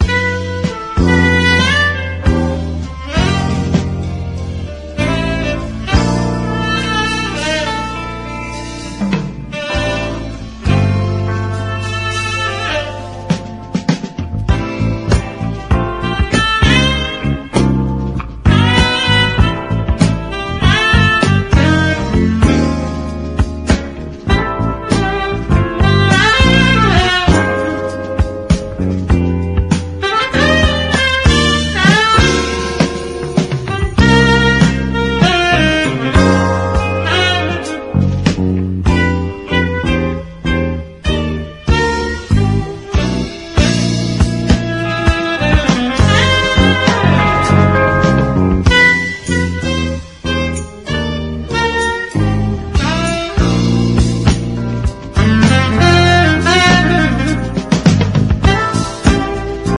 DRUM BREAK / JAPANESE GROOVE / DRUM / JAPANESE JAZZ
JAZZ FUNK / JAZZ ROCK
ヘヴィー・ヒッティングなドラムの強力なグルーヴィー・カヴァー！
ファンキーに打ちまくってます！